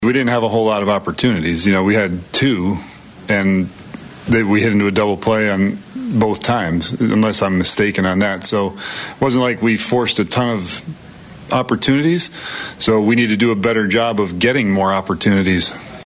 Royals Manager Matt Quatraro says they need to create more opportunities.